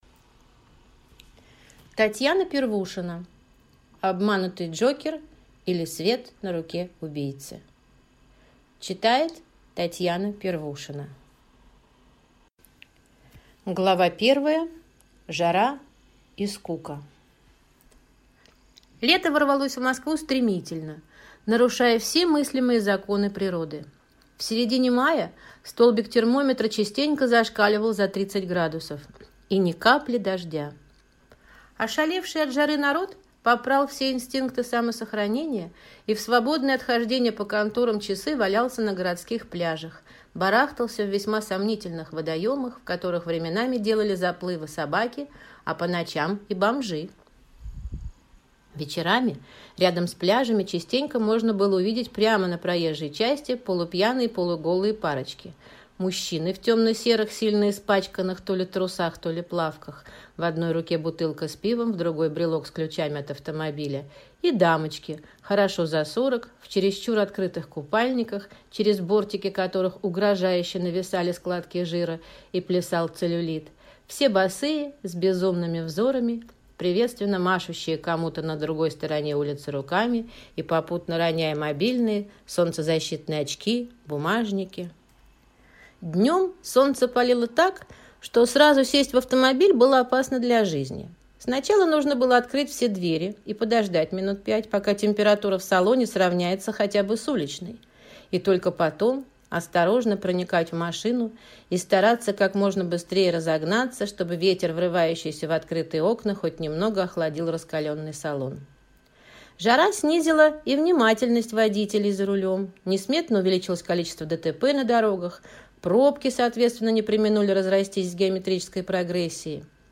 Aудиокнига Обманутый Джокер